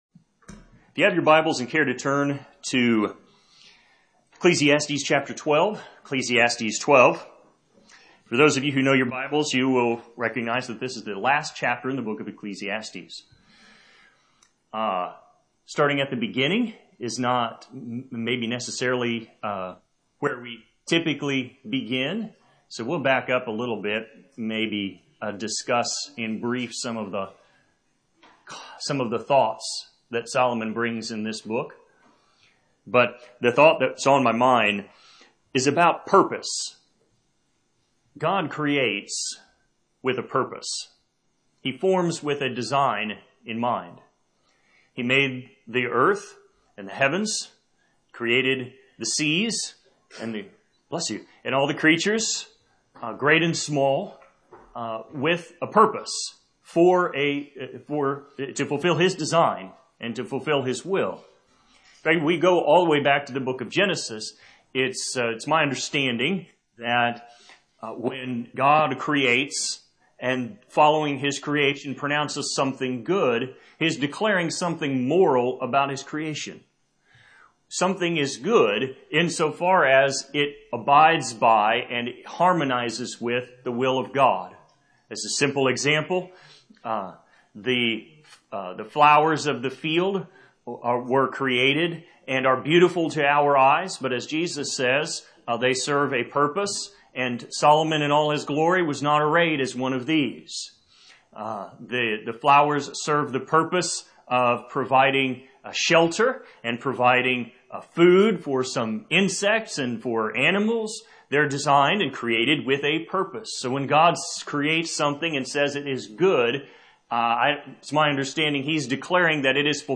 This sermon was recorded at Oxford Primitive Baptist Church Located in Oxford,Kansas